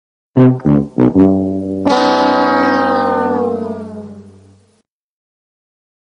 price_is_right_horn.m4a